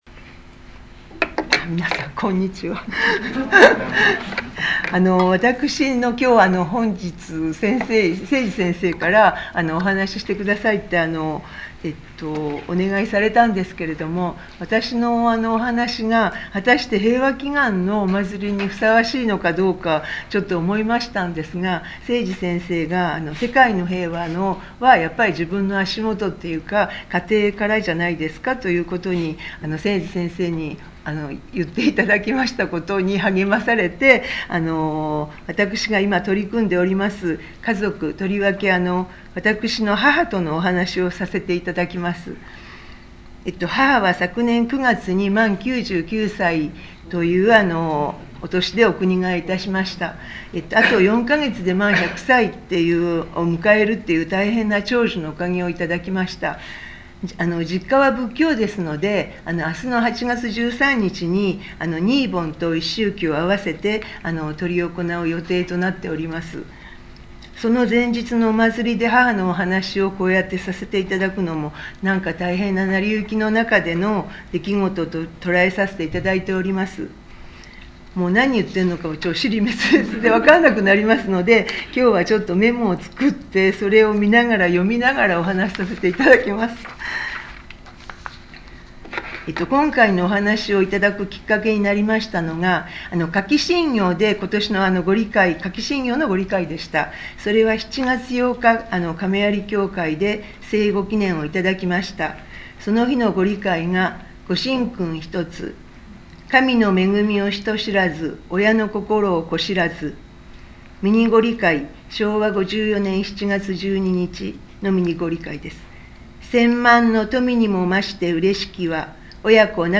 23.08.12 平和祈願大祭感話発表